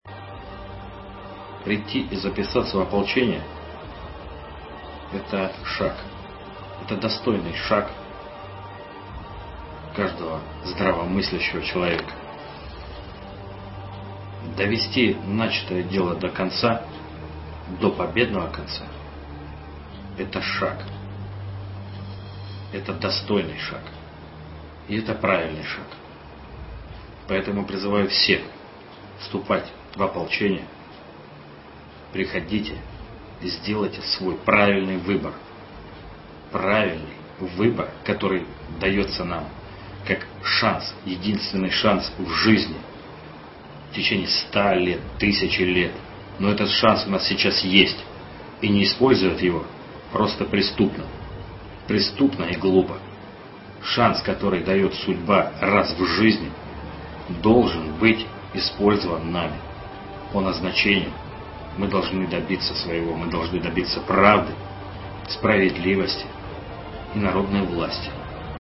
Из обращения Алексея Мозгового 8 сентября 2014 года